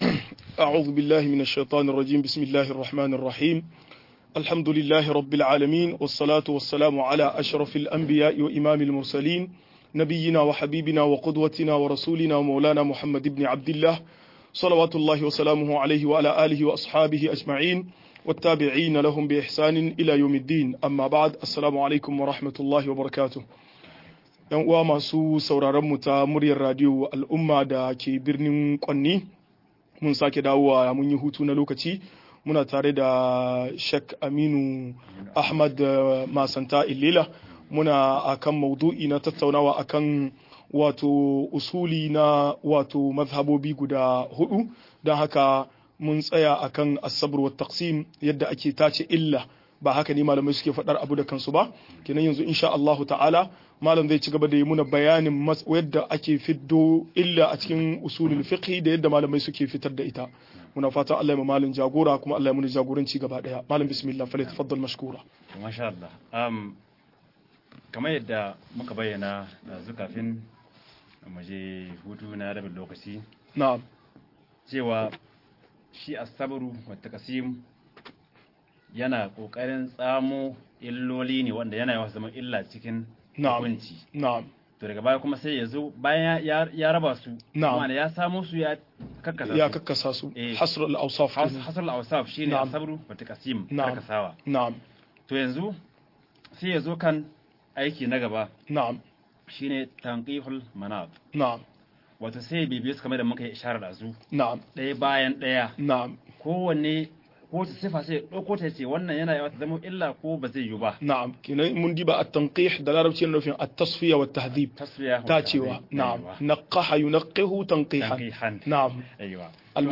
Asalin mazhabobin fiqhu-02 - MUHADARA